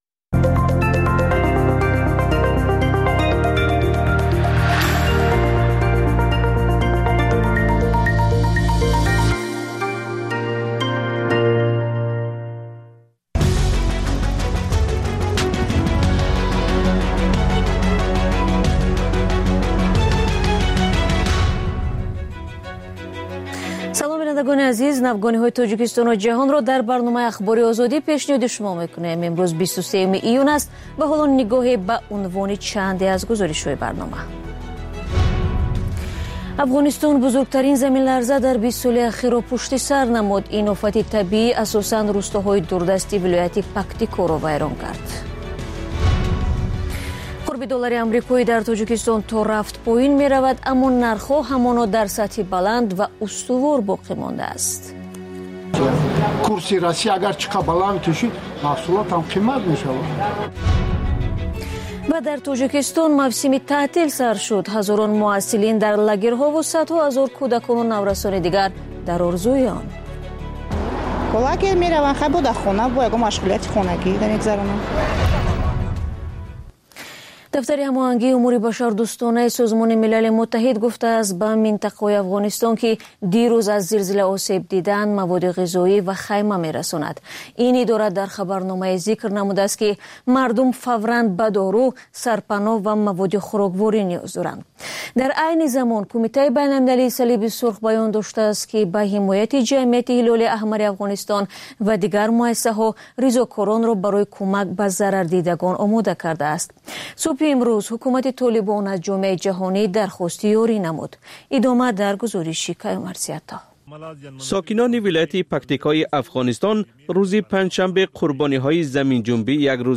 Фишурдаи охирин ахбори ҷаҳон, гузоришҳо аз Тоҷикистон, гуфтугӯ ва таҳлилҳо дар барномаи бомдодии Радиои Озодӣ.